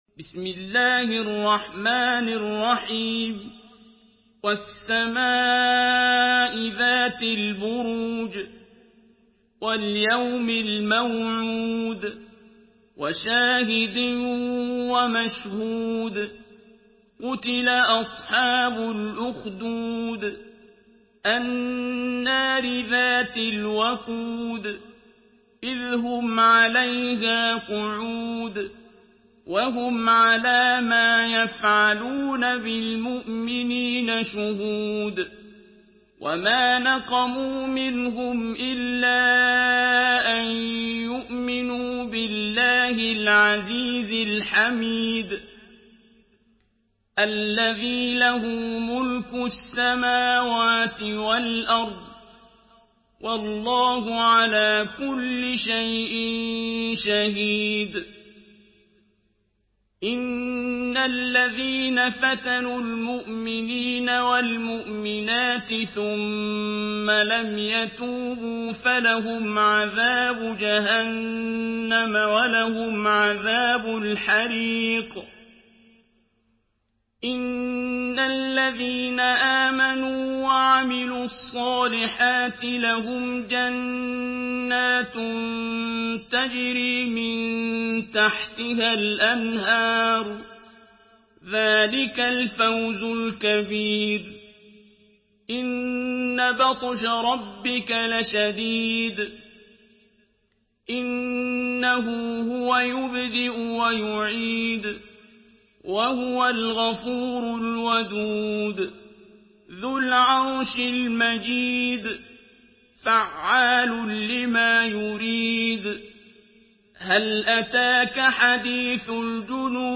Surah আল-বুরূজ MP3 by Abdul Basit Abd Alsamad in Hafs An Asim narration.
Murattal Hafs An Asim